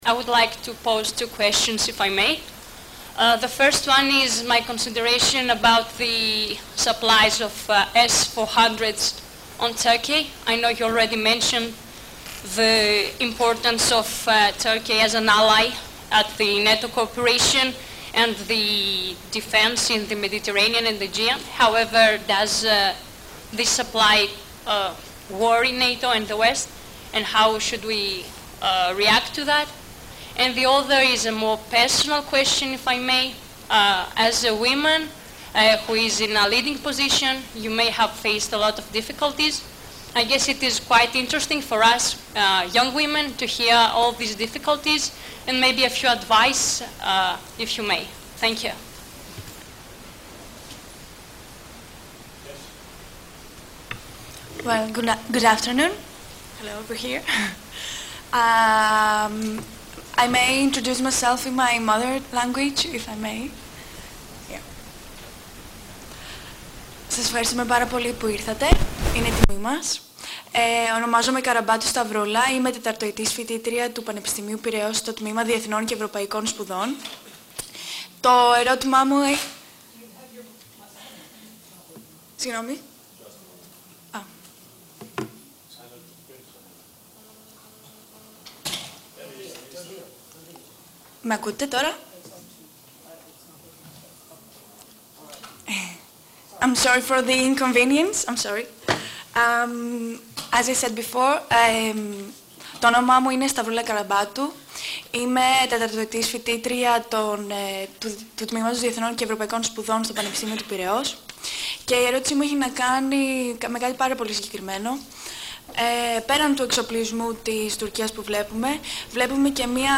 ENGLISH - Speech by NATO Deputy Secretary General Rose Gottemoeller at the townhall event with students, hosted by the Greek Association for Atlantic and European Cooperation 02 Mar. 2018 | download mp3